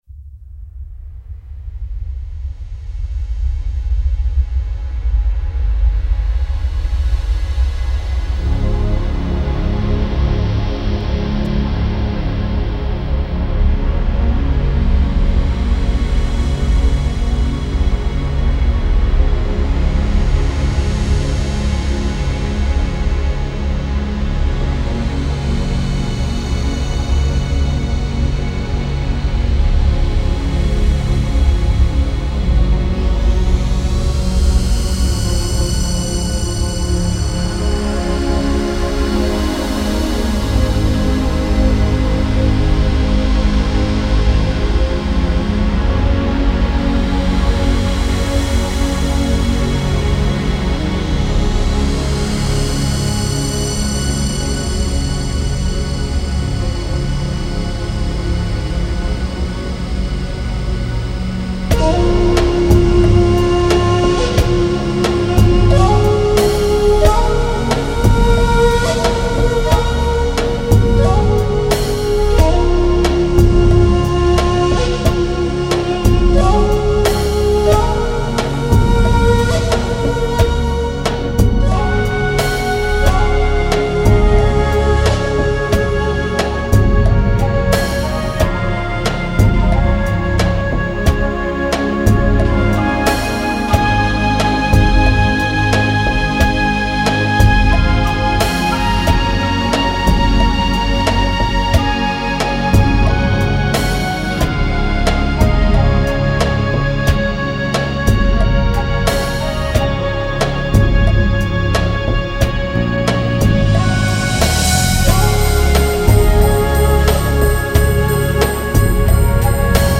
☆人聲與大自然音效呈現出絢麗而多變的音場變化，加上教堂鐘聲與聖歌的和諧莊嚴，使專輯呈現多變卻又溫馨的氣氛。